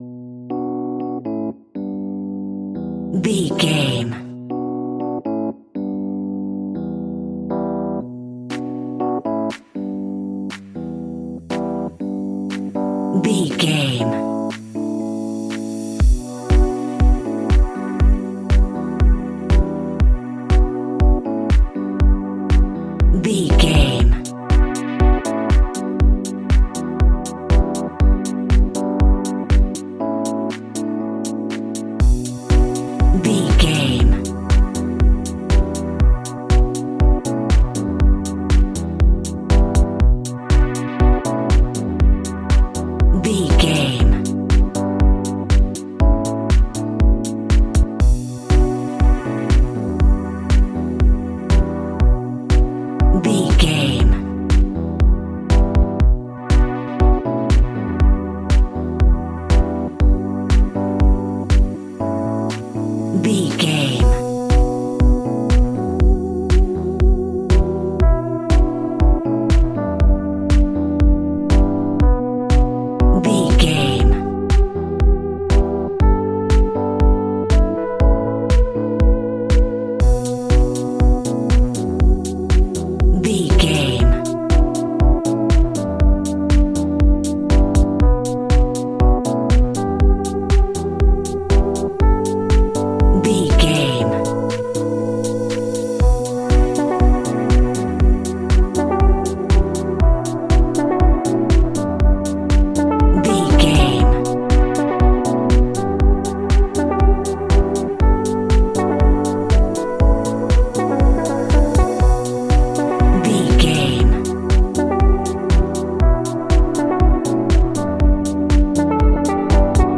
Tropical Dance Groove Full.
Aeolian/Minor
funky
groovy
uplifting
driving
energetic
drum machine
synthesiser
electric piano
house
electro house
funky house
synth bass